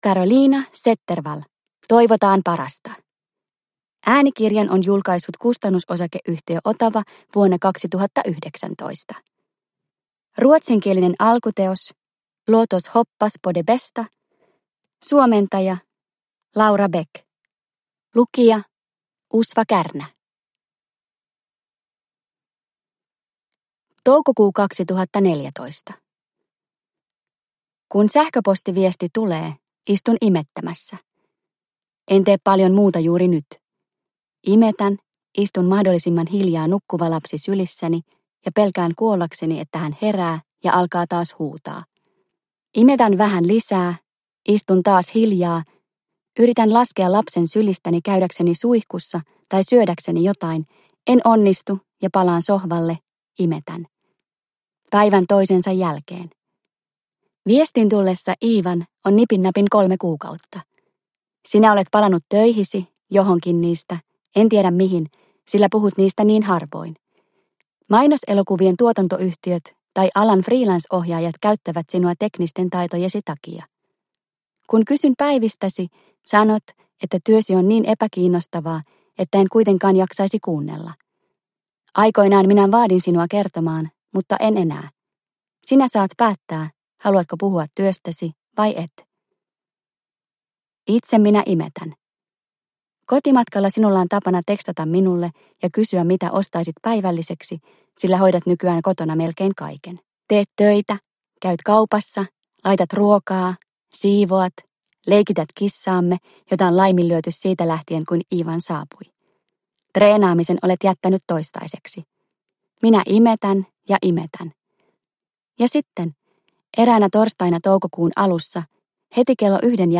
Toivotaan parasta – Ljudbok – Laddas ner